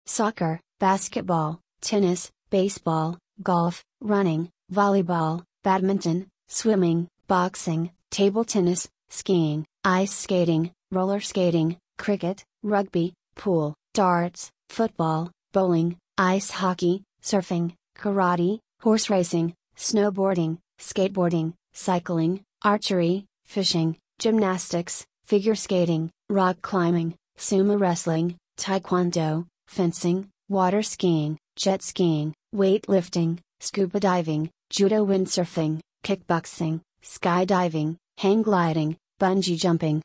Sports_pronunciation_EduMNC-kxzuqogx.mp3